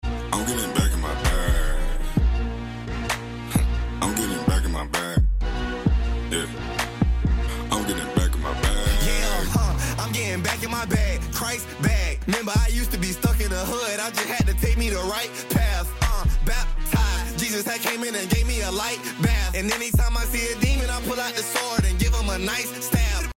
christianrap